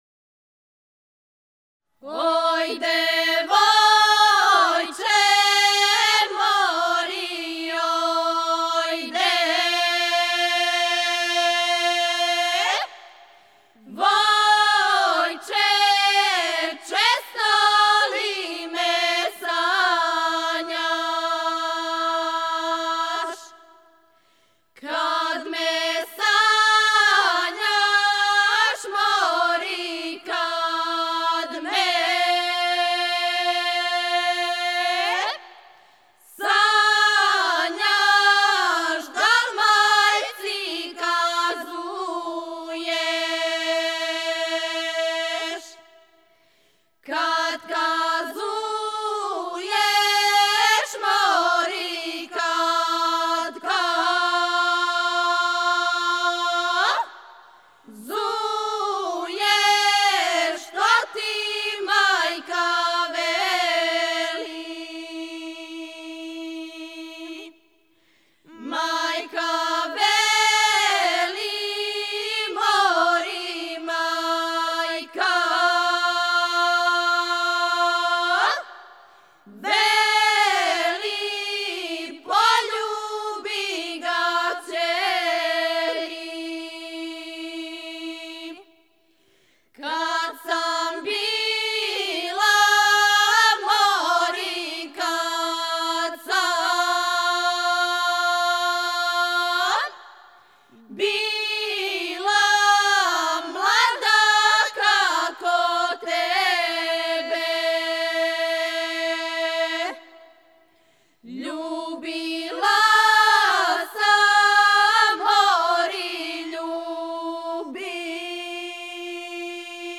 Женска група
Солисти на удараљкама